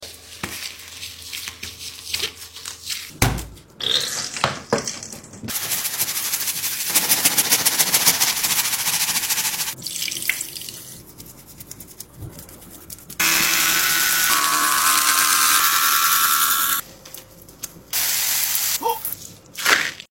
CLEANING SQUISHY ASMR🐤🐤Chicks#asmr